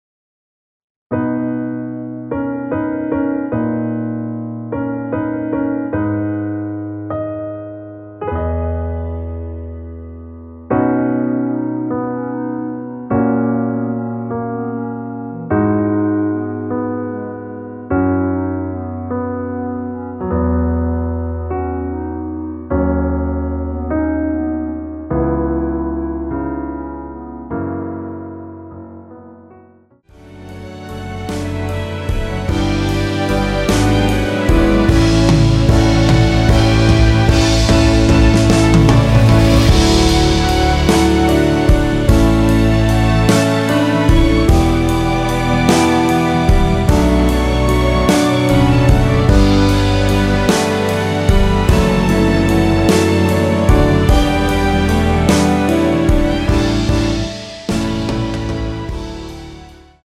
원키에서(-6)내린멜로디 포함된 MR입니다.
앞부분30초, 뒷부분30초씩 편집해서 올려 드리고 있습니다.